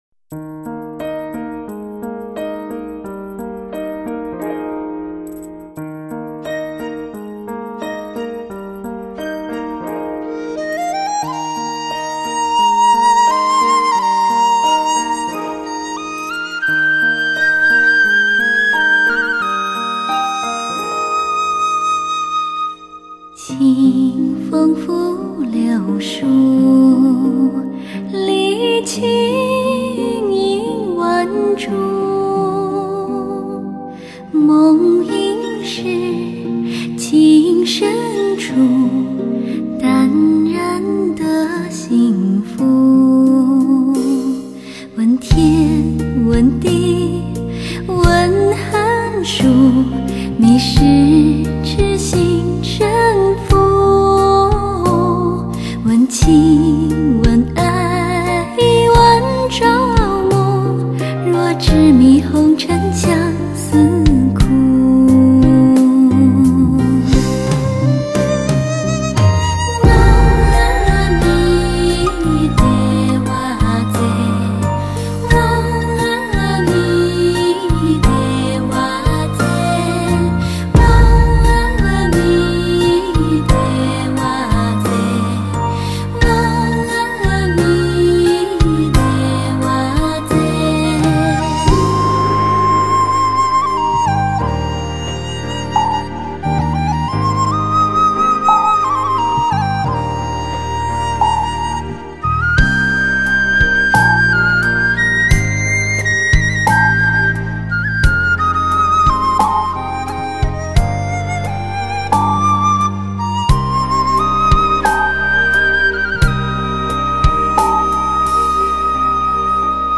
闭目思静  净化心灵  超凡脱俗
清新典雅  细细体味人生真、善、美